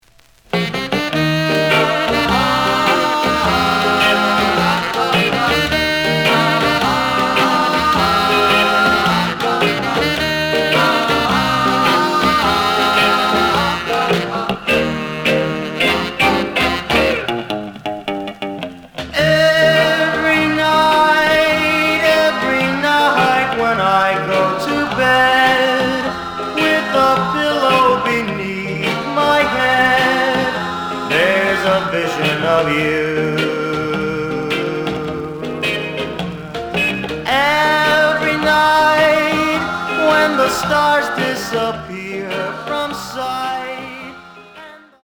試聴は実際のレコードから録音しています。
●Genre: Rhythm And Blues / Rock 'n' Roll
●Record Grading: VG+ (盤に若干の歪み。多少の傷はあるが、おおむね良好。)